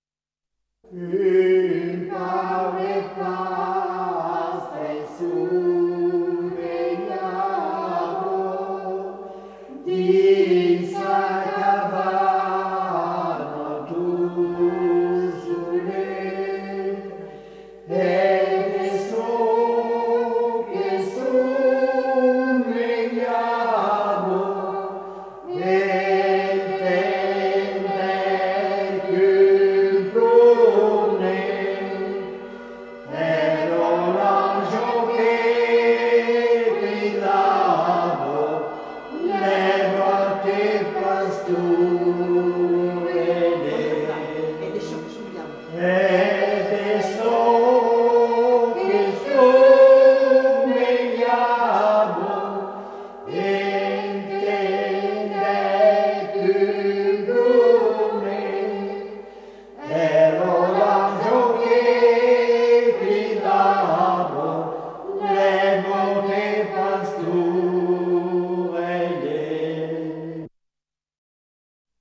Lieu : Mas-Cabardès
Genre : chant
Type de voix : voix d'homme ; voix de femme
Production du son : chanté
Notes consultables : L'ensemble vocal n'est pas identifié.